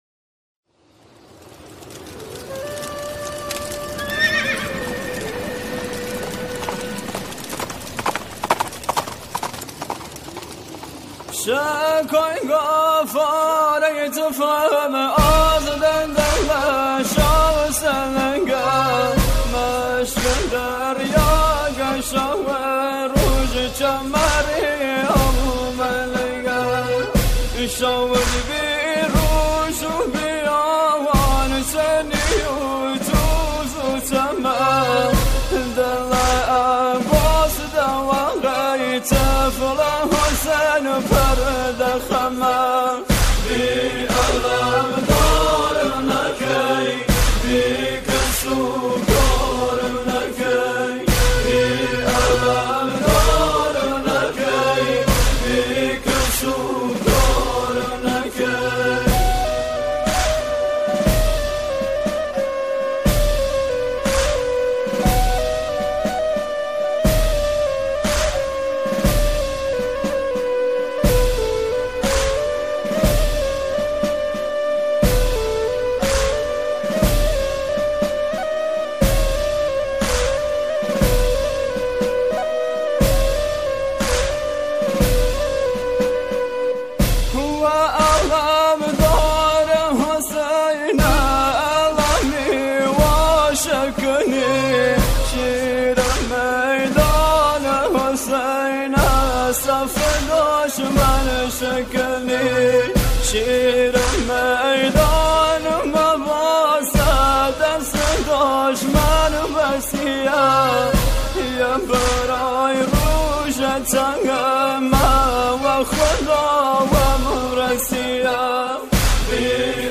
سرودهای حضرت عباس علیه السلام